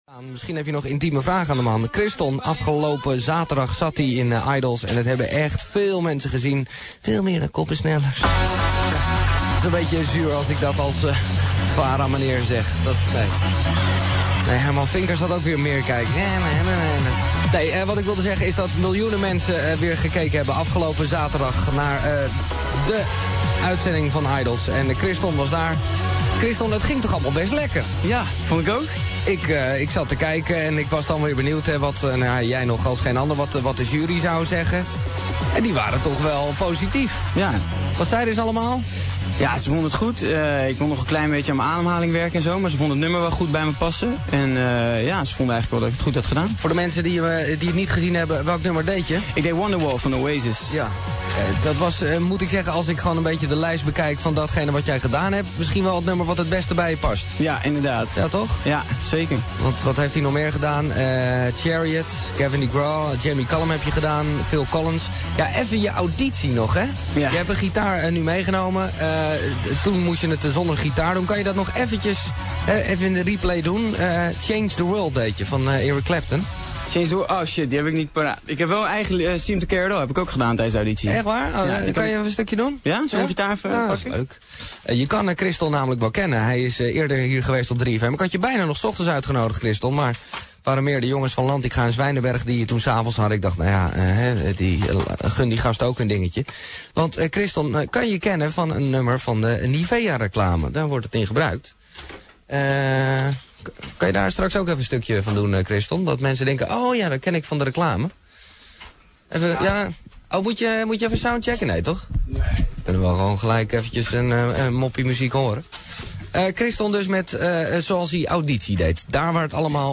Giel praat met hem over zijn Idols-avontuur en hij zingt het nummer dat hij ontzettend graag bij Idols had willen zingen 'I Don't Want To Be' van Gavin Degraw.